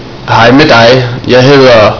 [Hai mayh dai, yai hayther *your name*]